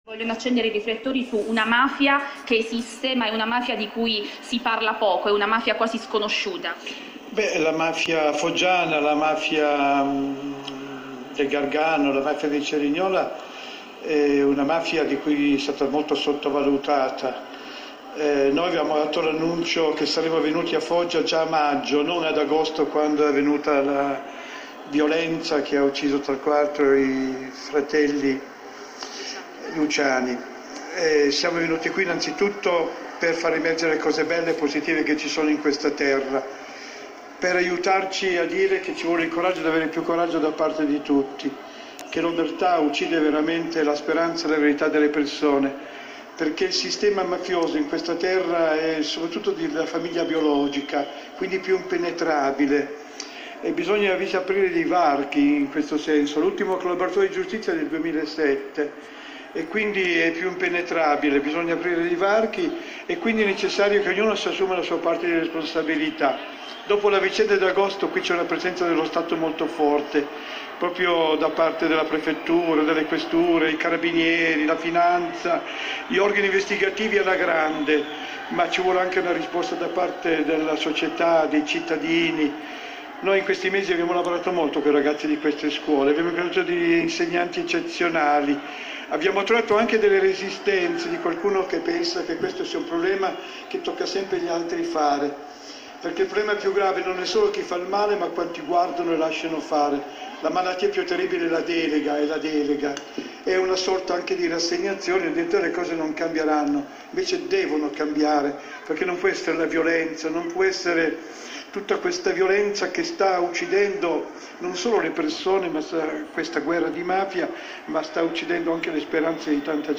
21 marzo 2018 – Il fondatore dell’Associazione “Libera”, don Luigi Ciotti, e’ stato intervistato questa mattina nel corso della trasmissione ” Agora’”, in onda su Rai3, dalle ore 8 alle 10.